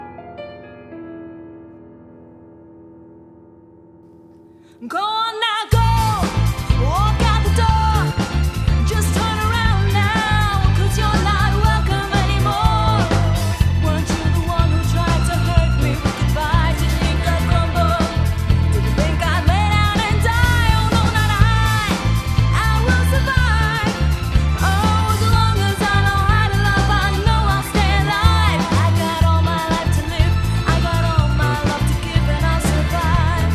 Keyboards.
Drums.
Guitar.
Bass Guitar (Backing Vocals).